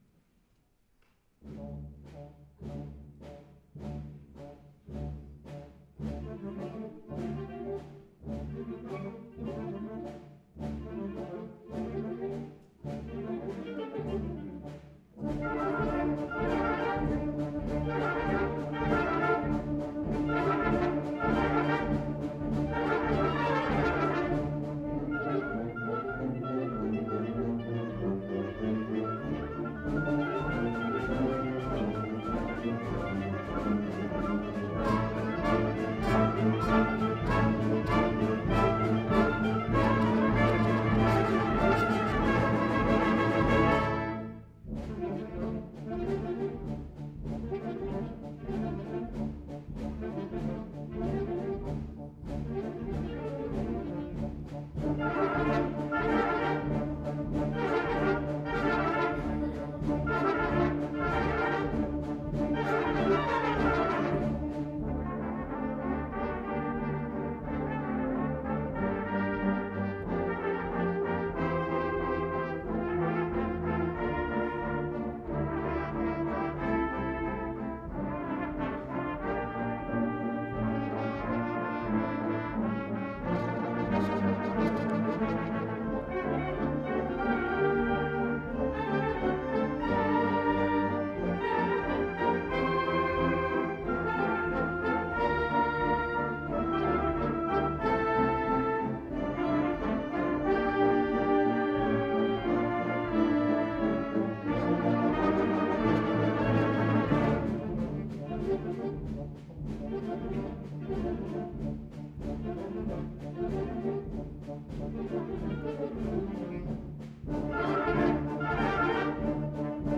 2014 Summer Concert